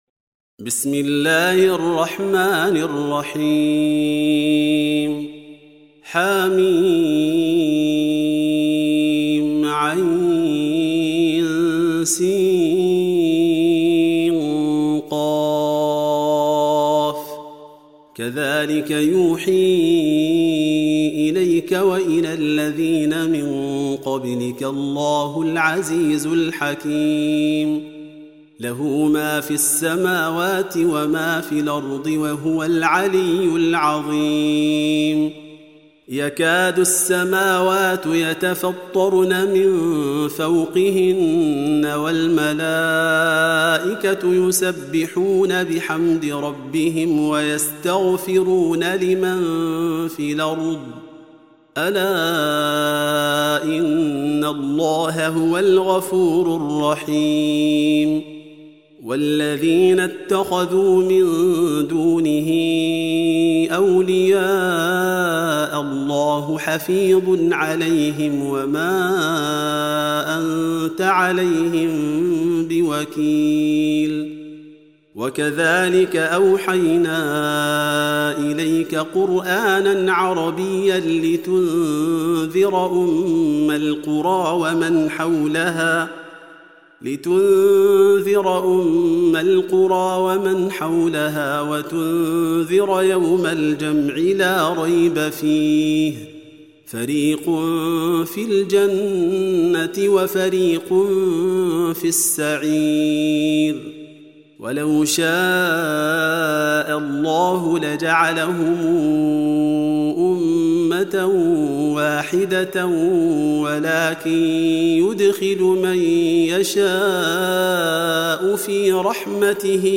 Surah Repeating تكرار السورة Download Surah حمّل السورة Reciting Murattalah Audio for 42. Surah Ash-Sh�ra سورة الشورى N.B *Surah Includes Al-Basmalah Reciters Sequents تتابع التلاوات Reciters Repeats تكرار التلاوات